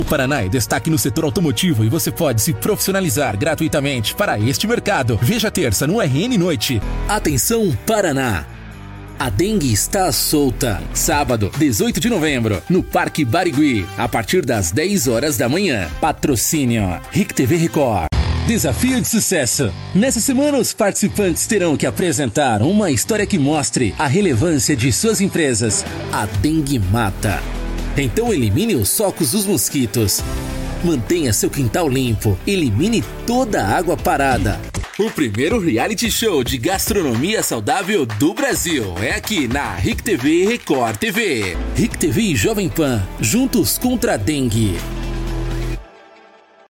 Voz - Tv:
Impacto
Animada